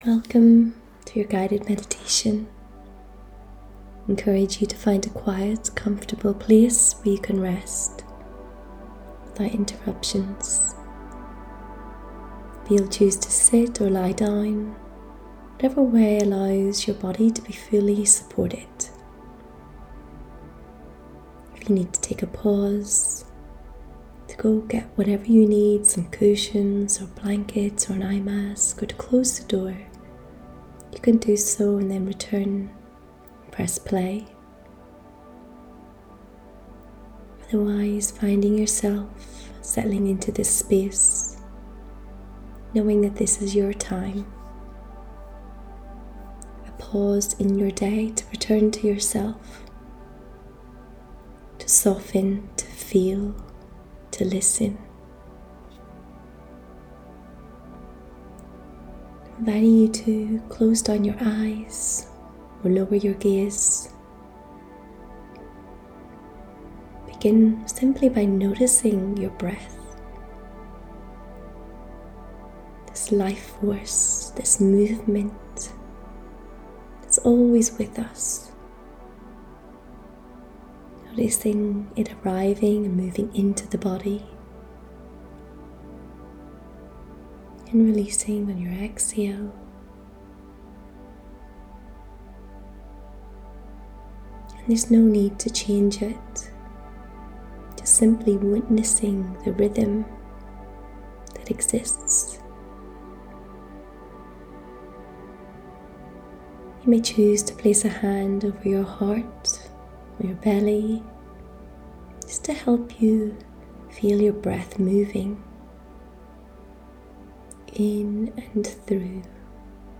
Click the above image to listen to experience a guided meditation and reconnection practice.